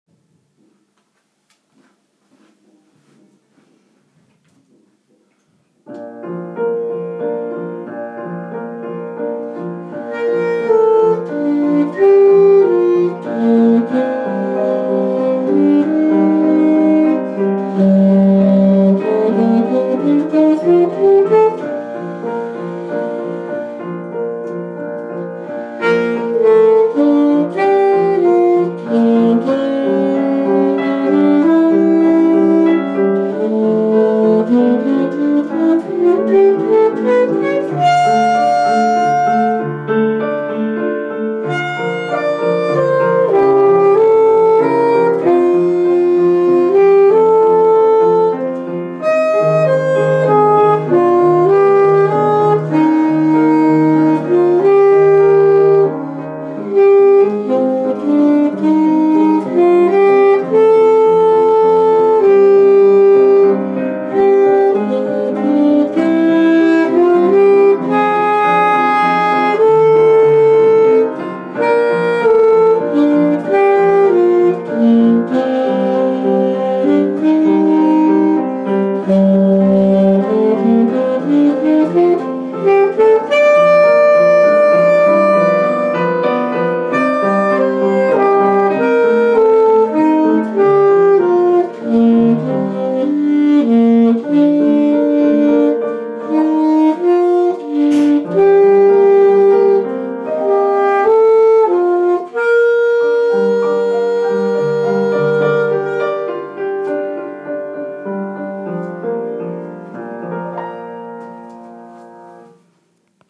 Saxophone The Swan